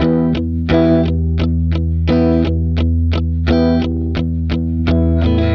Track 02 - Guitar Rhythm 01.wav